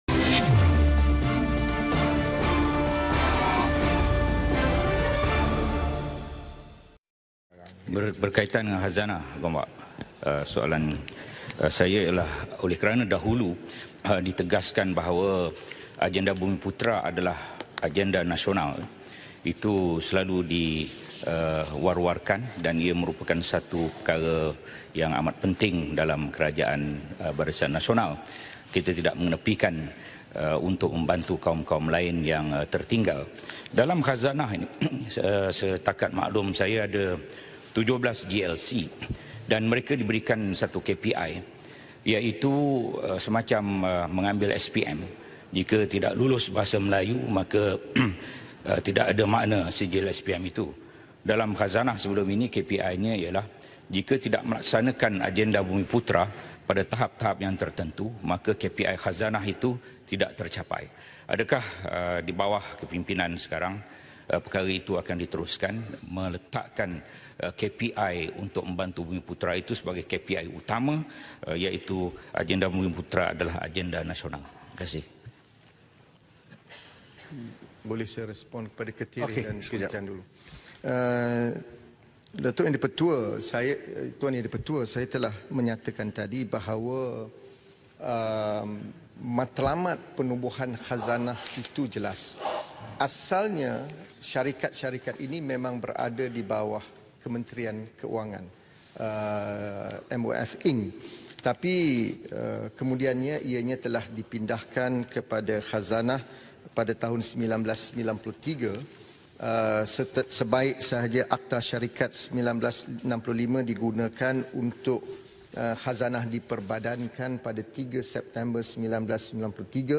Dengarkan respon dari Menteri Kewangan yang juga Ahli Parlimen Gombak, Datuk Seri Azmin Ali kepada soalan yang diajukan oleh Ahli Parlimen Pontian, Datuk Ahmad Maslan dan Ahli Parlimen Ketereh, Tan Sri Annuar Musa berkenaan Khazanah Nasional.